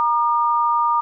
dtmf_star.ogg